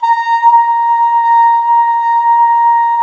Index of /90_sSampleCDs/Roland L-CDX-03 Disk 2/BRS_Cup Mute Tpt/BRS_Cup Ambient